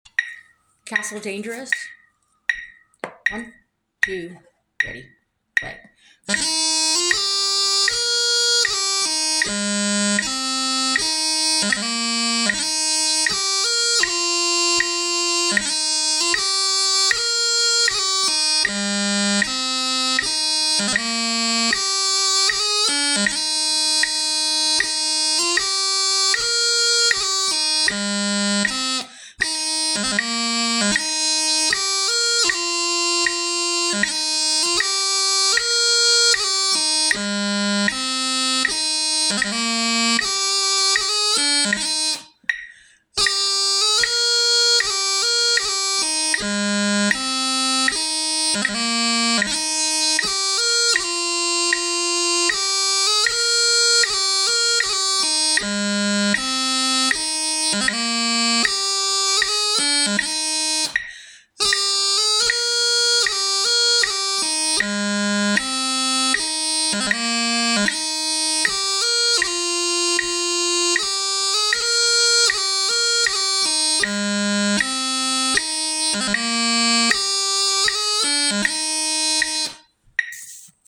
3/4 Marches